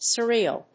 音節sur・re・al 発音記号・読み方
/səríːəl(米国英語), sɜ:ˈi:l(英国英語)/